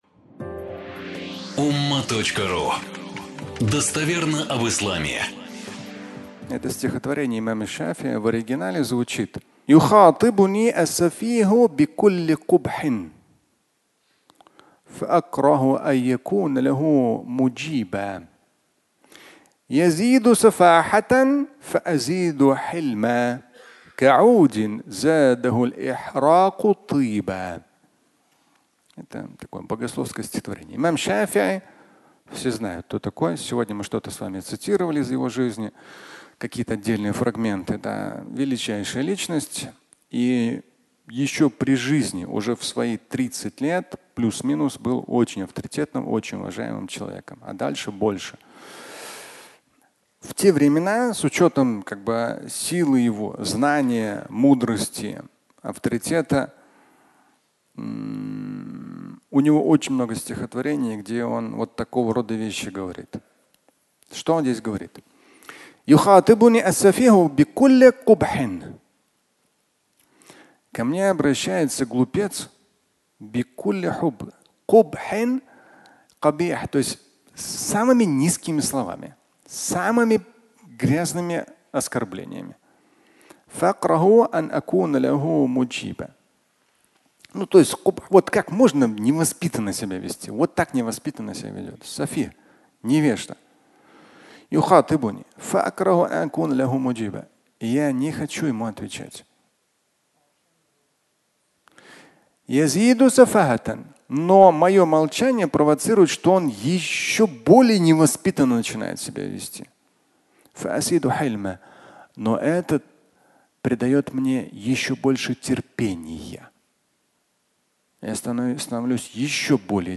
Благоухать (аудиолекция)
Пятничная проповедь